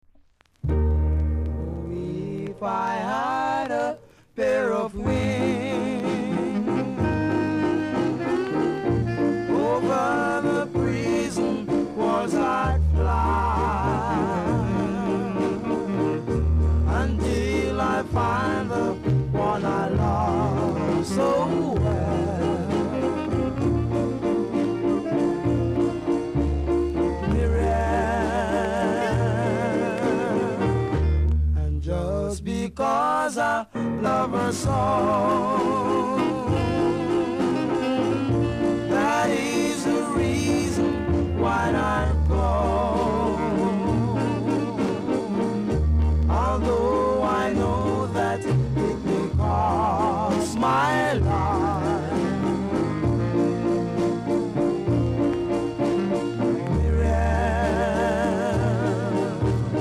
※チリ、パチノイズが少しあります。